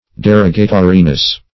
derogatoriness \de*rog"a*to*ri*ness\, n.
derogatoriness.mp3